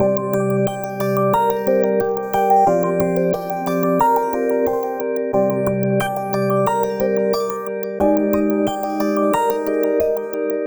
Omnisphere2で作成。
同じコード進行を3トラック用意して、
BPMをアンニュイ気分で90にしました。
このアンニュイなOmnisphere2サウンドを、